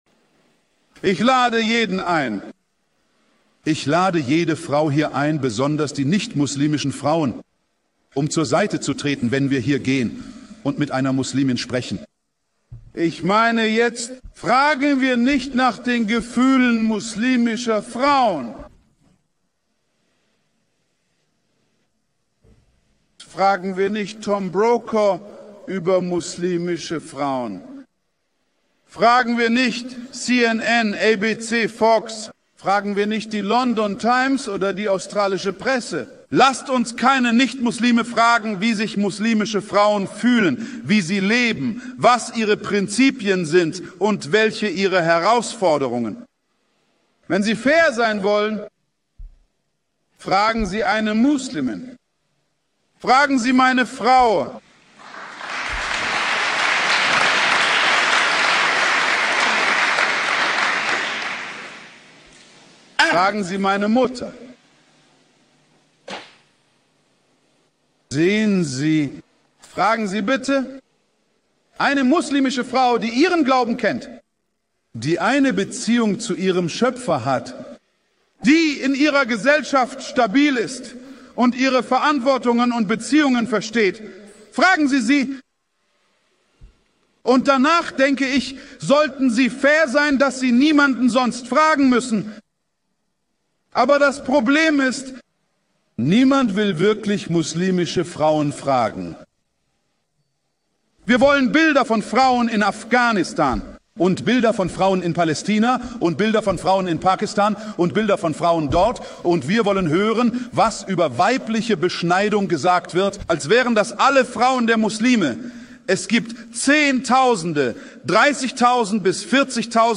Das Video ist ein wunderschöner Ausschnitt aus einer der Vorlesungen